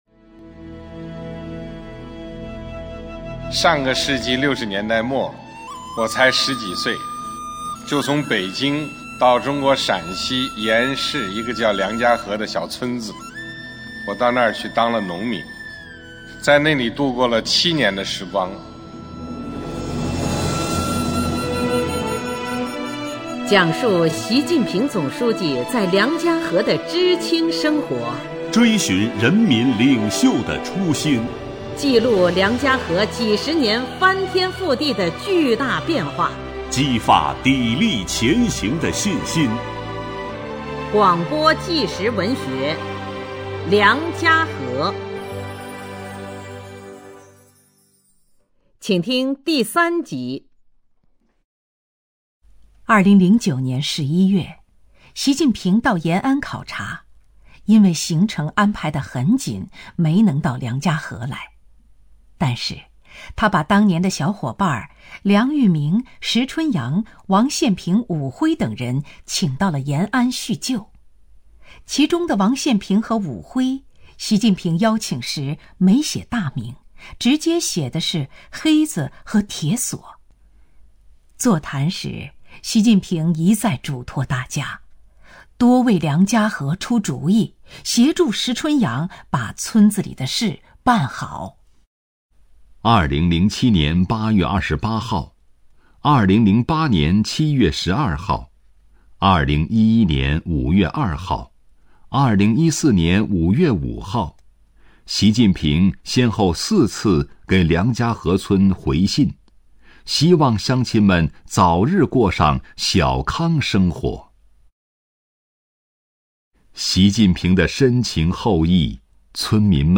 广播纪实文学《梁家河》第三集：“逃离”学习讲堂